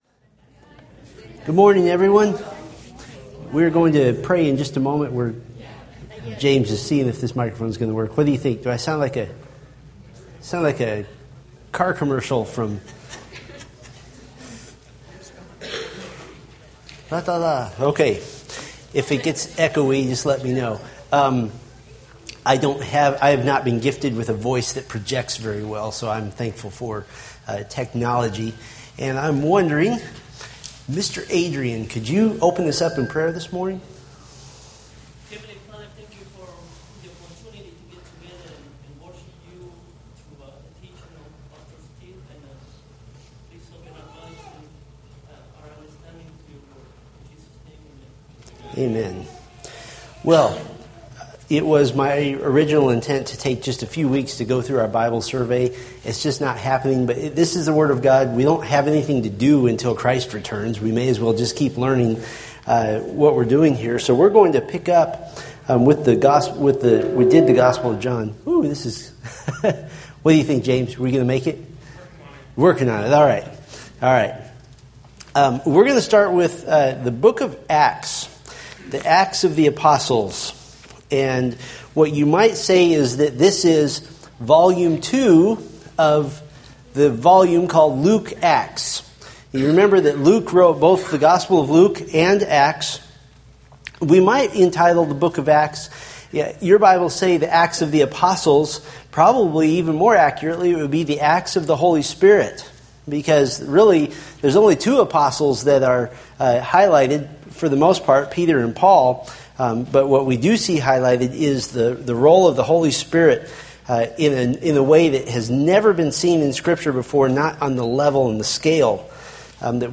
Date: Jun 2, 2013 Series: Fundamentals of the Faith Grouping: Sunday School (Adult) More: Download MP3